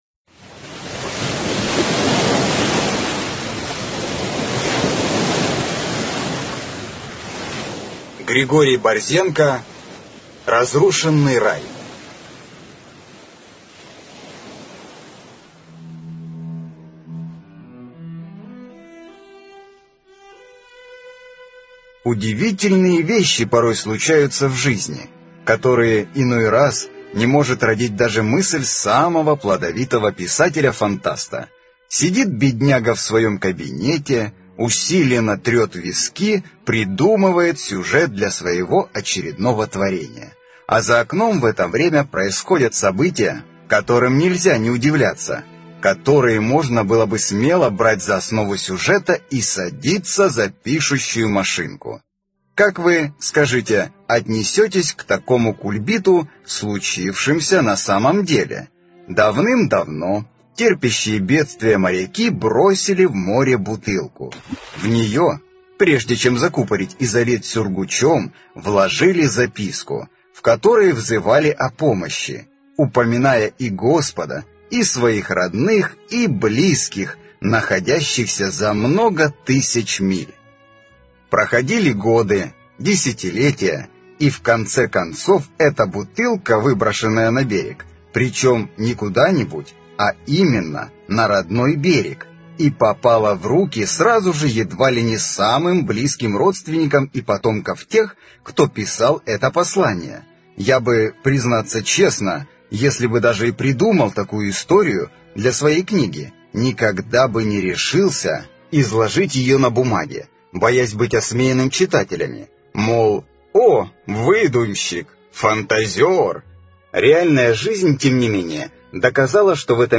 Аудиокнига Разрушенный рай | Библиотека аудиокниг
Прослушать и бесплатно скачать фрагмент аудиокниги